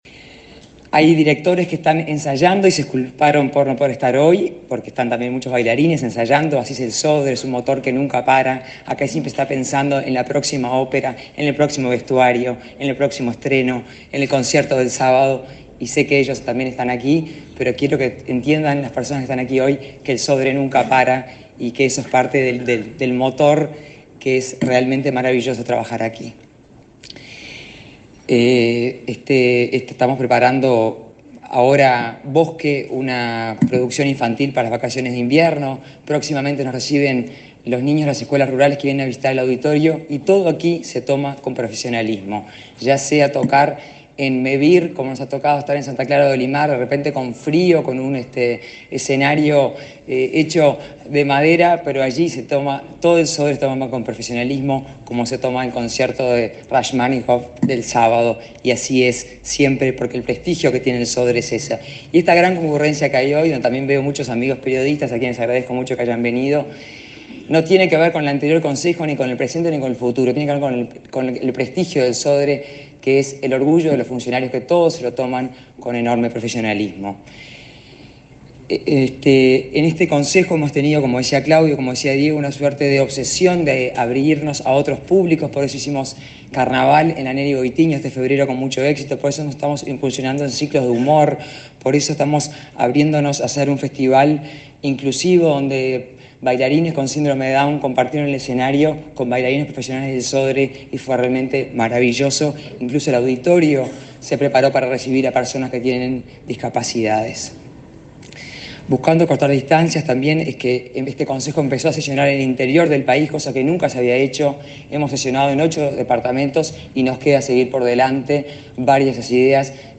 Palabra de integrantes de nuevo directorio del Sodre 06/07/2022 Compartir Facebook X Copiar enlace WhatsApp LinkedIn Este miércoles 5 se celebró el acto de toma de posesión del cargo del nuevo directorio del Sodre. Su presidenta, Adela Dubra; el vicepresidente Claudio Aguilar; y el consejero Diego Silveira Rega, destacaron la relevancia del organismo en la cultura nacional.